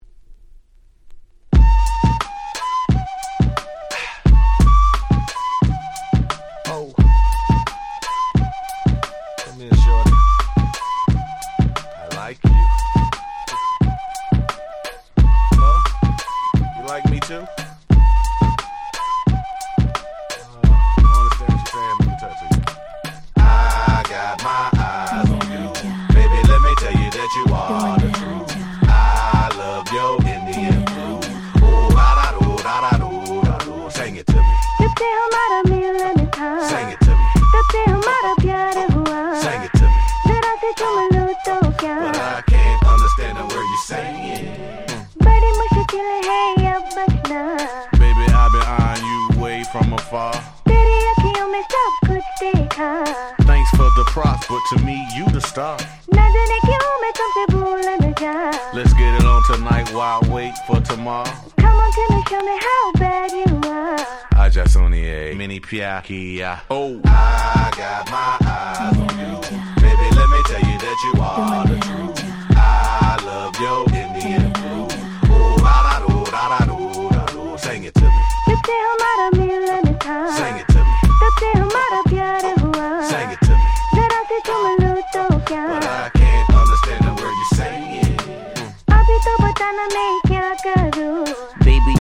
03' Super Nice Hip Hop !!
シタールの音色が幻想的でエキゾチックな名曲。
この時期こういうインド風な曲調ちょっぴり流行りましたよね。